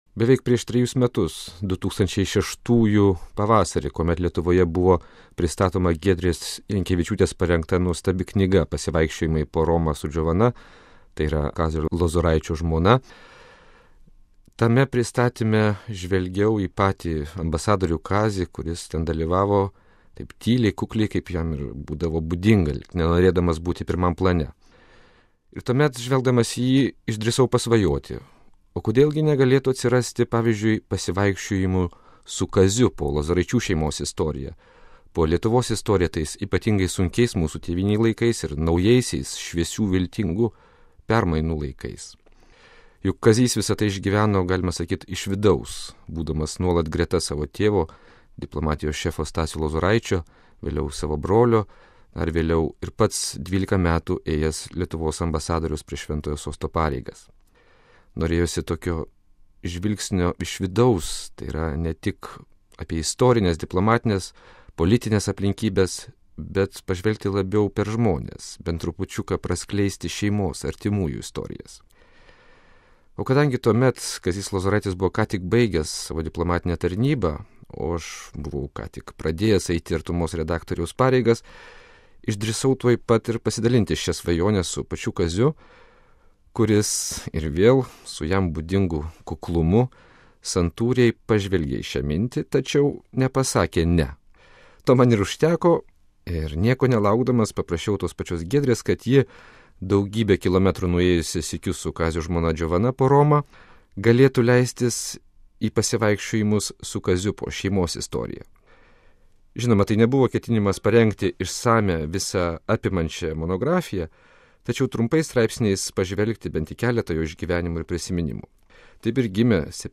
Apie ką tik išėjusią knygą prie mūsų mikrofono kalba jos sudarytoja